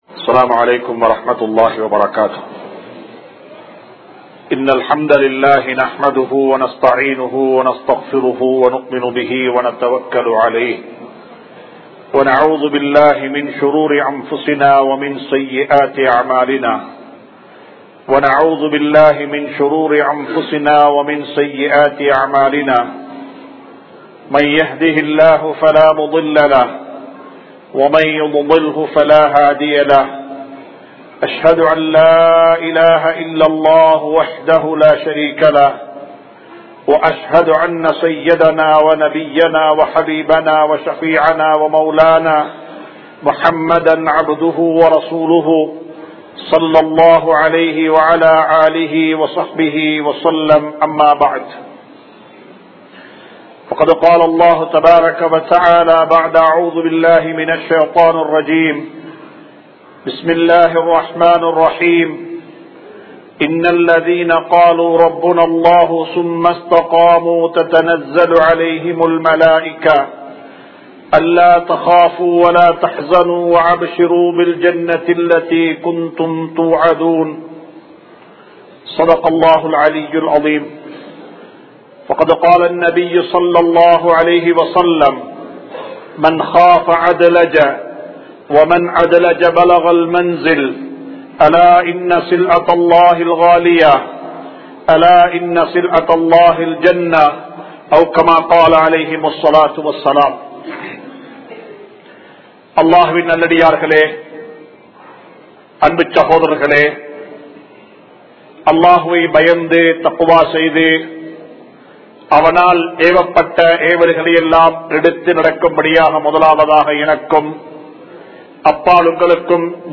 Kudumbathitku Selavu Seyvathu Tharmam!(குடும்பத்திற்கு செலவு செய்வது தர்மம்!) | Audio Bayans | All Ceylon Muslim Youth Community | Addalaichenai